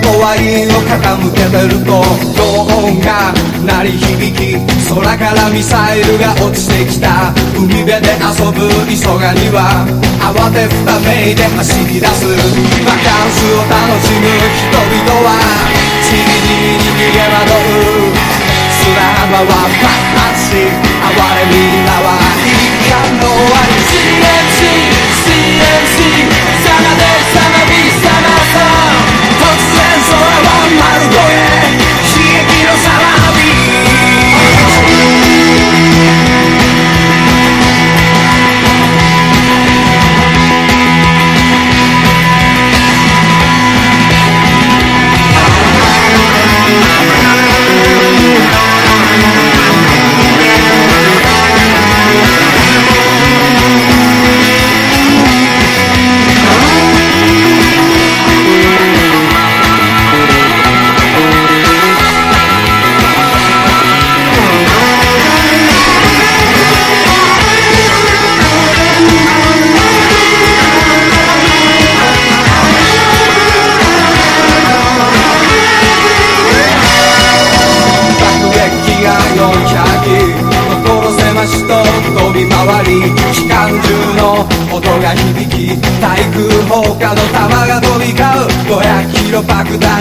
# 60-80’S ROCK